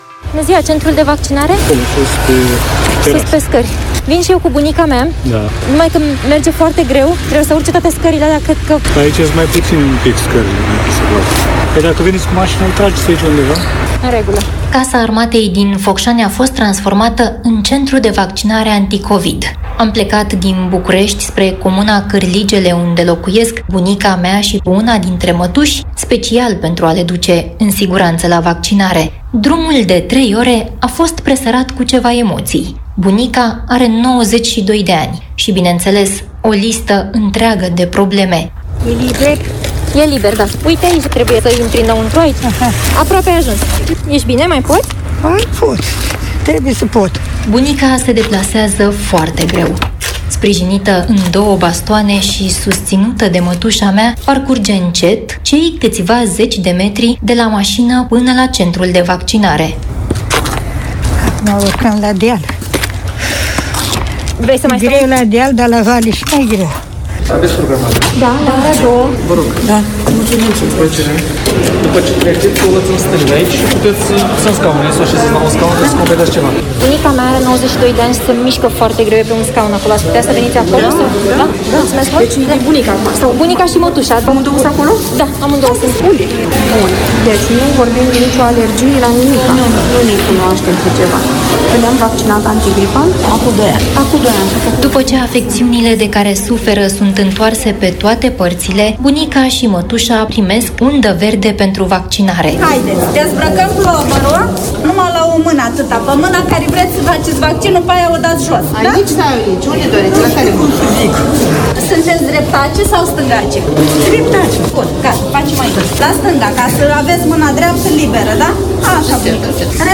Reportaj Lumea Europa FM: Pe munte, în comunism / AUDIO